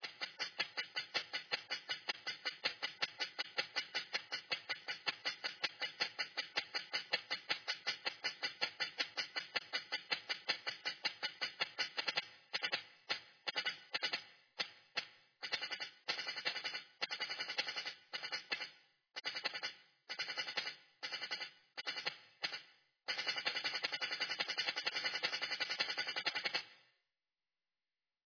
Percussion Shaker Loop
Royalty free percussion shaker loop sound effect.
32kbps-Shaker-2-loop.mp3